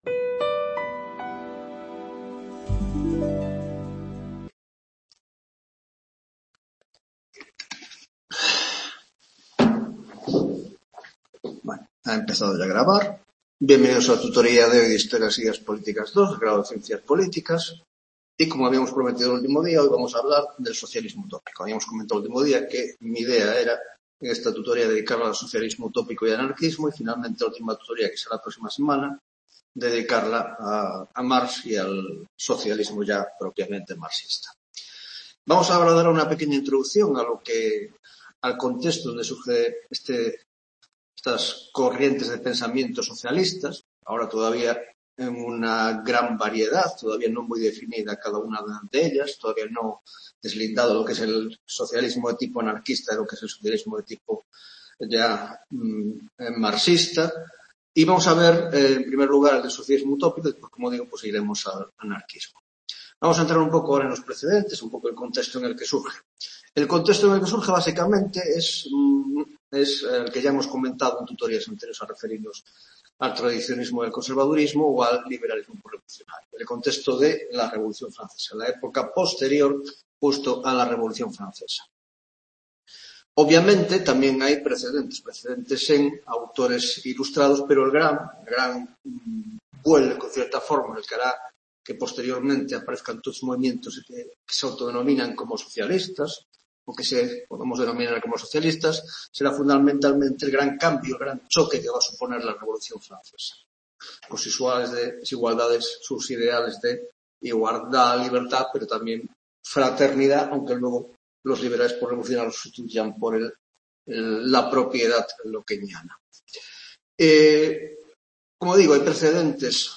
10ª Tutoría de Historia de las Ideas Políticas 2 (Grado de Ciencias Políticas) - Socialismo Utópico y Anarquismo (1ª parte)